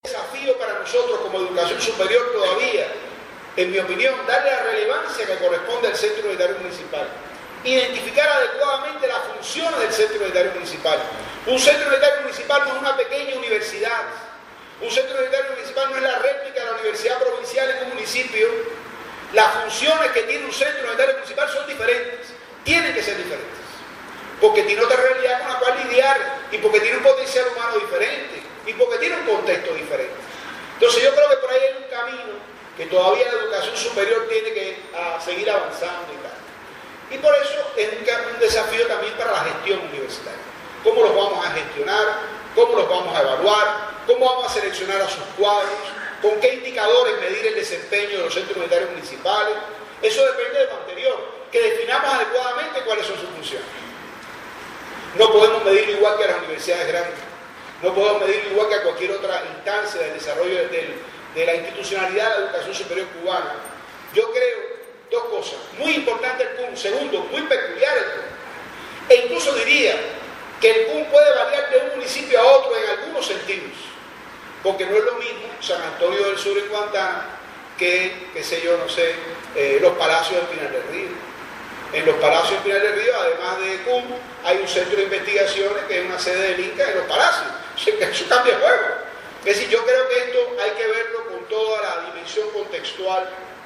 durante el VII Congreso de desarrollo local celebrado en Bayamo, Granma